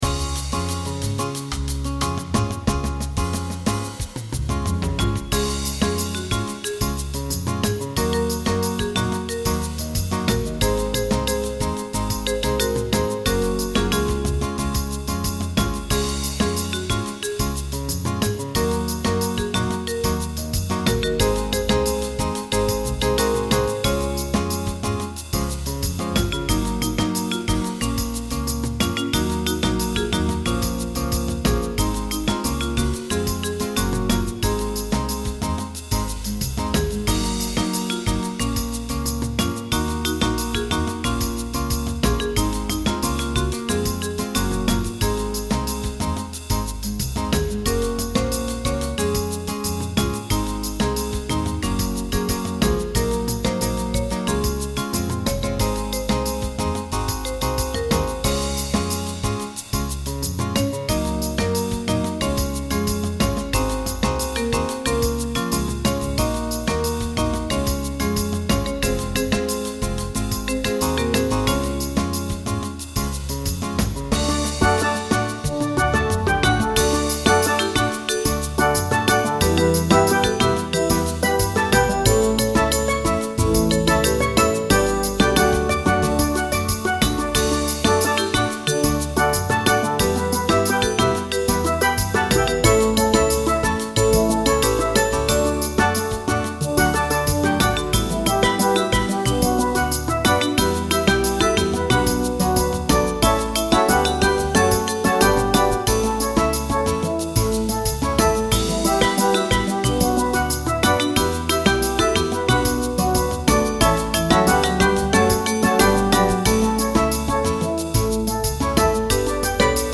It's happy and catchy.
Recording from MIDI